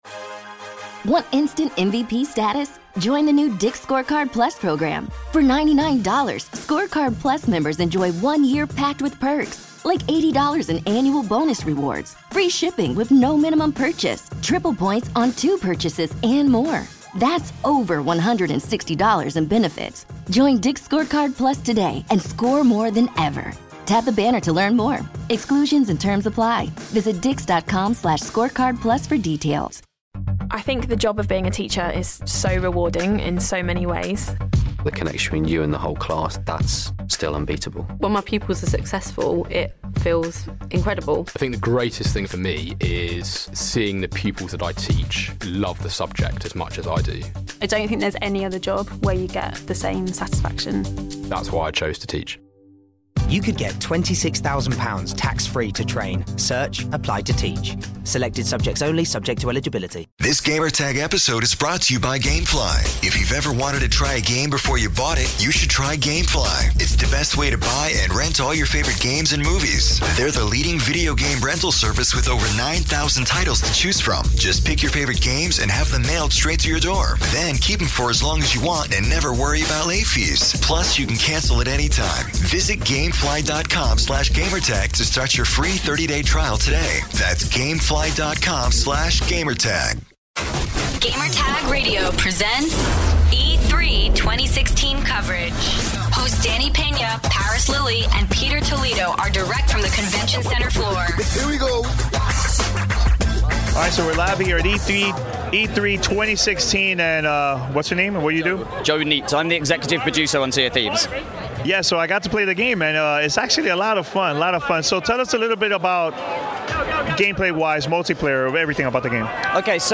E3 2016: Sea of Thieves - Xbox Media Showcase / Rare E3 Dev Interview